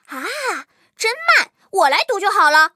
M18地狱猫查看战绩语音.OGG